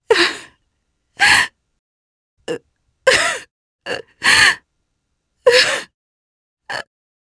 Demia-Vox_Sad_jp.wav